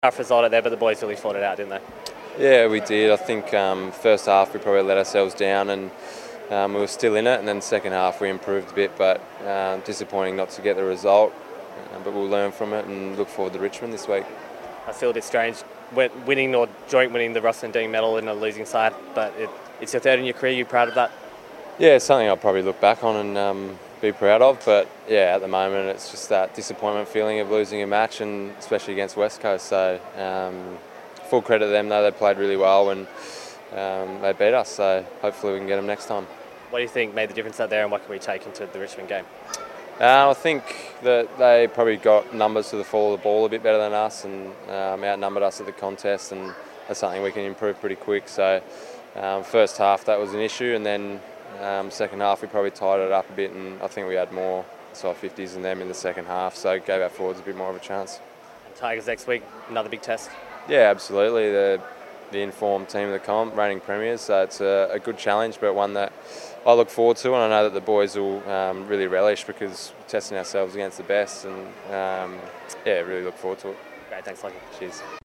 Lachie Neale chats to Docker TV after Sunday's Derby.